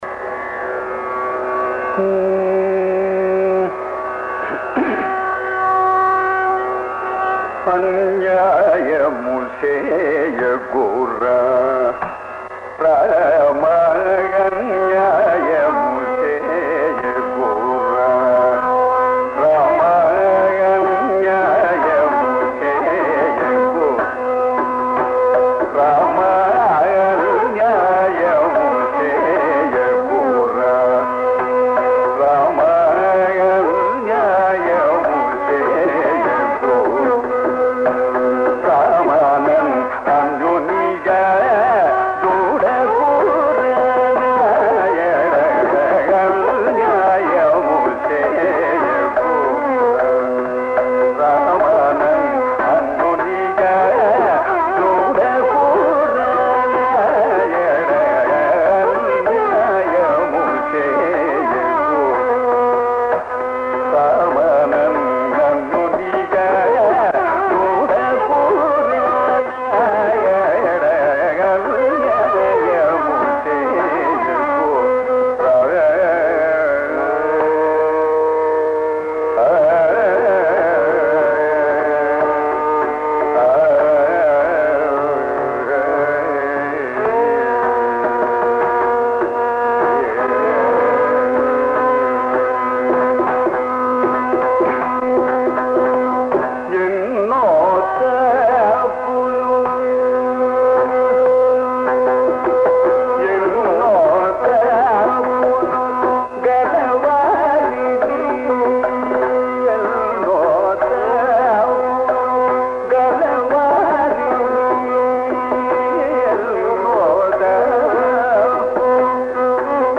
The contrast is provided by Vidvan Nedunuri Krishnamurthi, whose presentation of the same kriti is in modern Kapi.
Clip 5: Sangita Kalanidhi Nedunuri Krishnamurthi renders